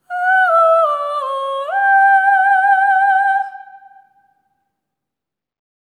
ETHEREAL09-L.wav